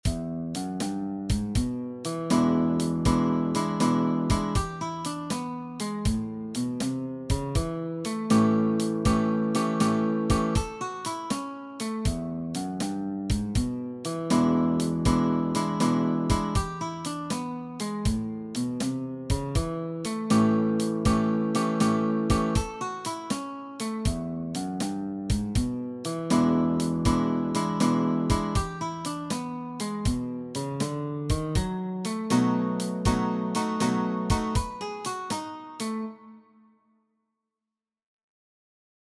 Blues-typischer 12-Takter